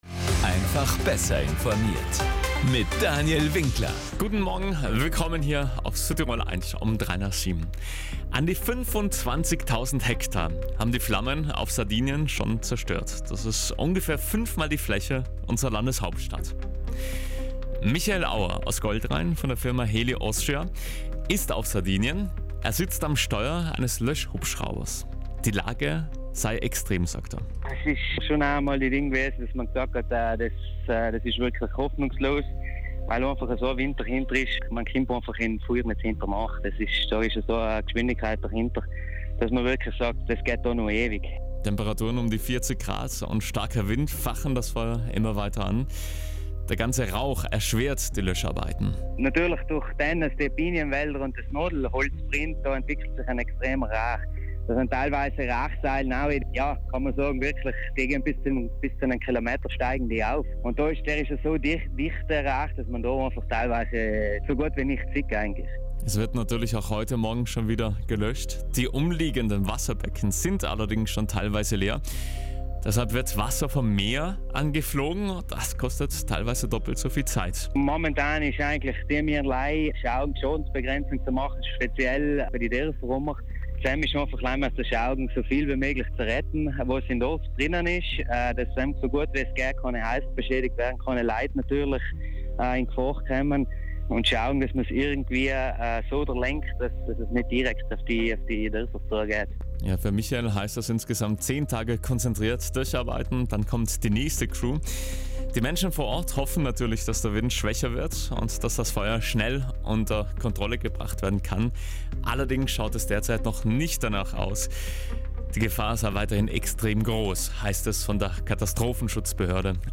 Wie schwierig der Kampf gegen das Feuer derzeit ist, hat er uns erzählt.